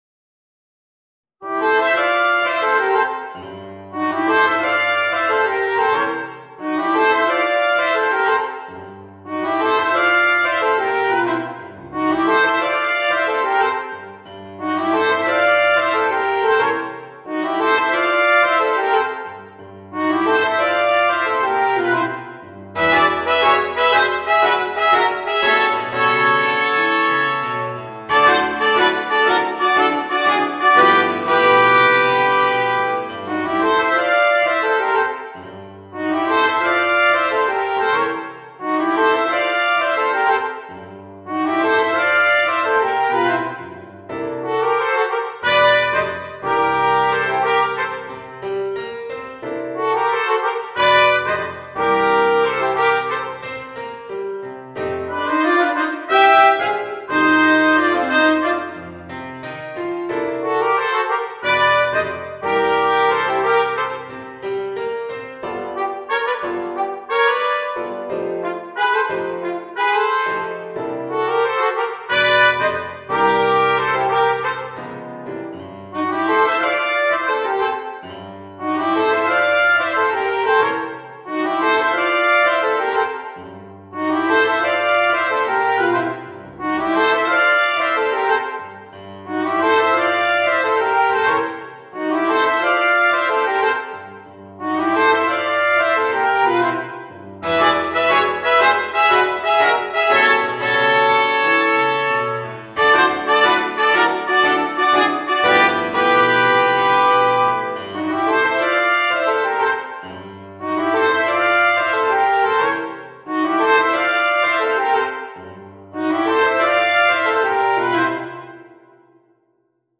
Voicing: Alto Saxophone, Trumpet w/ Audio